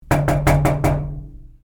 有关确定提醒音效素材的演示模板_风云办公